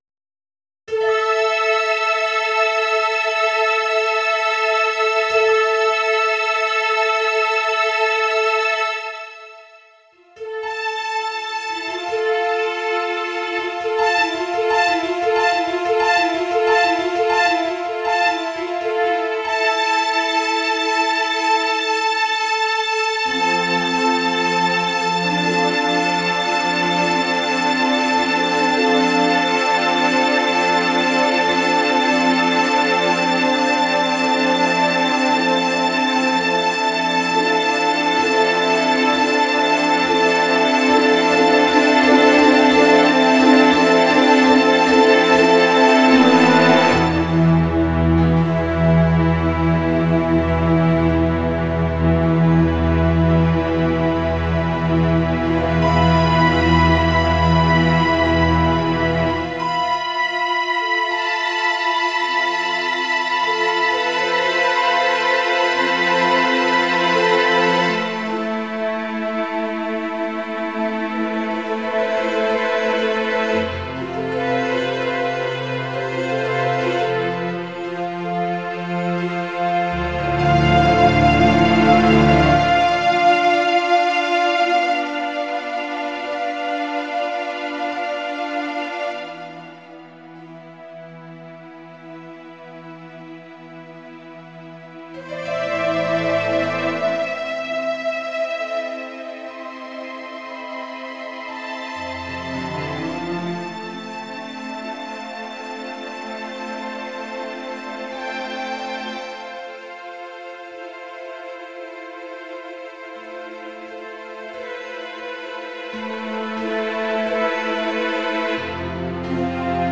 arreglo sinfónico